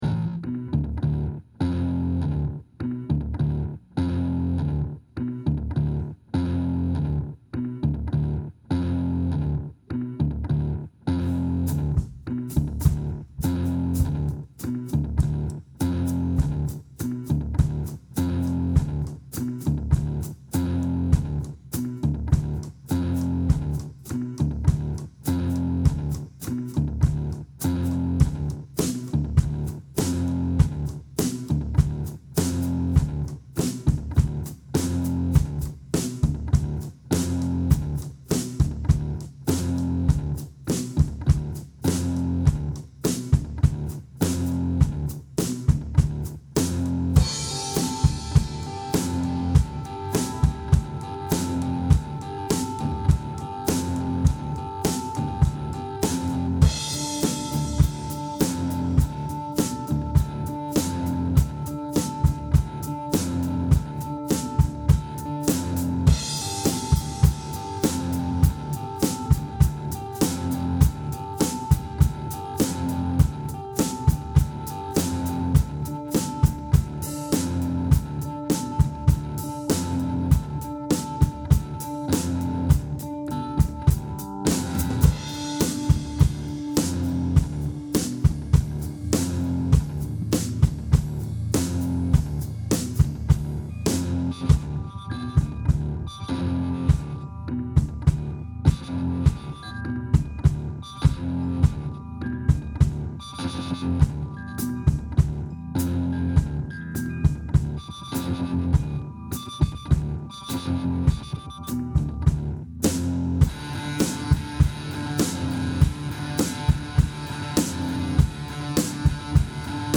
Dark crawling riff with some soloing over top.. 1 take to do both.